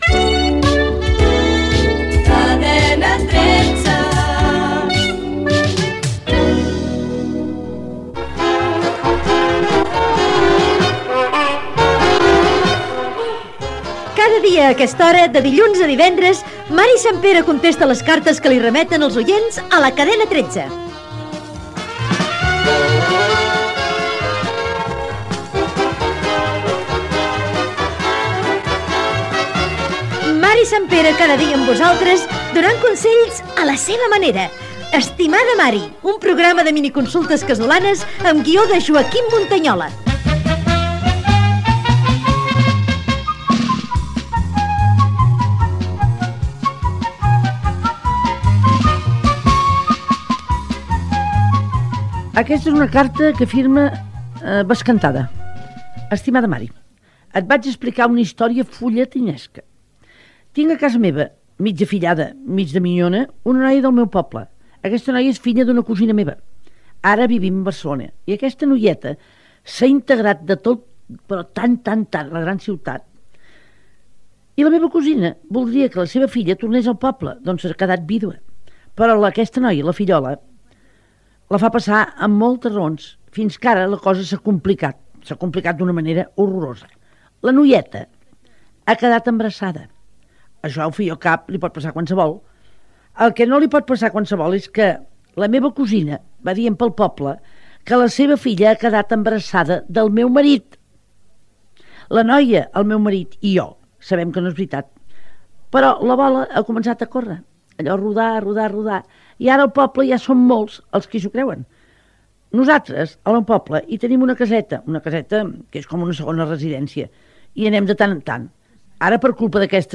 Careta del programa. Mary Santpere llegeix i contesta les cartes dels oients a la seva manera i al seu estil, indicatiu musical de la cadena.